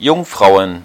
Ääntäminen
Ääntäminen Tuntematon aksentti: IPA: /ˈjʊŋˌfʀaʊ̯ən/ Haettu sana löytyi näillä lähdekielillä: saksa Käännöksiä ei löytynyt valitulle kohdekielelle. Jungfrauen on sanan Jungfrau monikko.